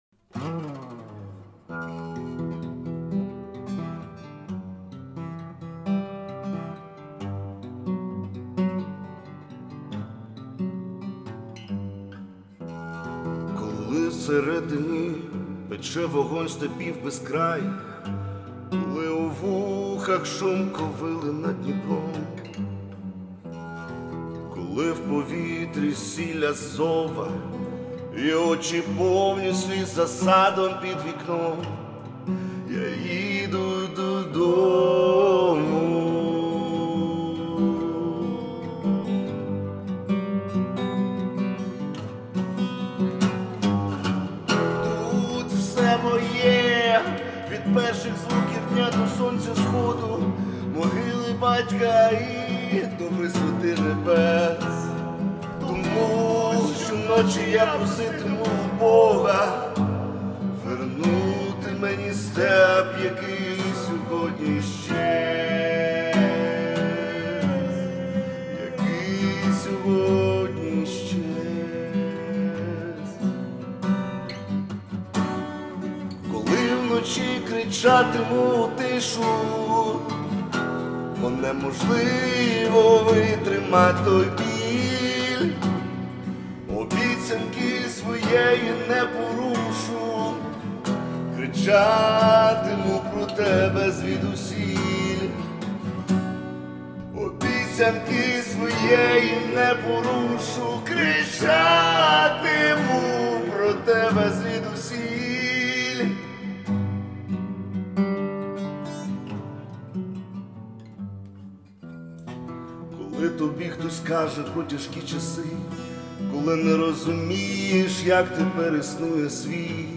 ТИП: Пісня
СТИЛЬОВІ ЖАНРИ: Ліричний
ВИД ТВОРУ: Авторська пісня